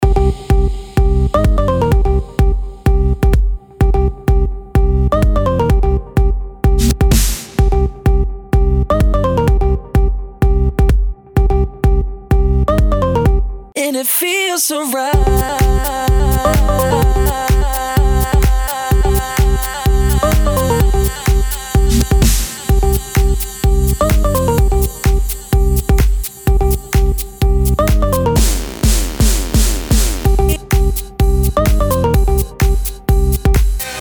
Красивый клубный сэмпл, наложенный на бас и бит!